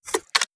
Annie_Rifle_Reload.wav